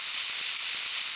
electro.ogg